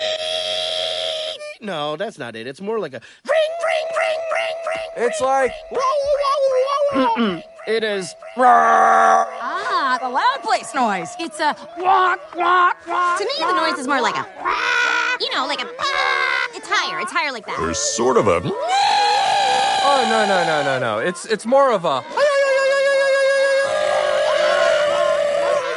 Hoppers Characters Screaming
Hoppers-Characters-Screaming.mp3